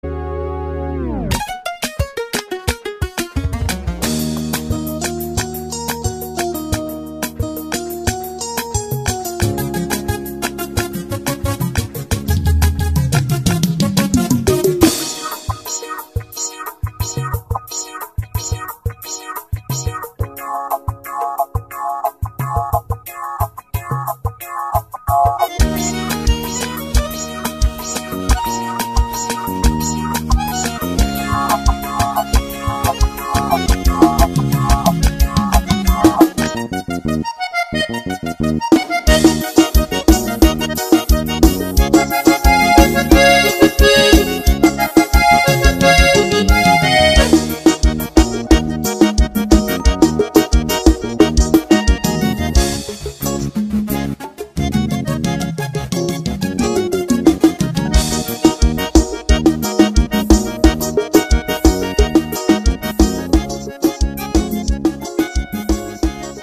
Composição: Playback.